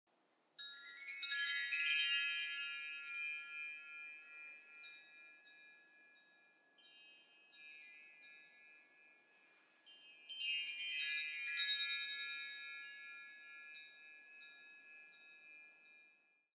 Meinl Sonic Energy Hand Chime 14"/36 cm - Bronze (HC9B)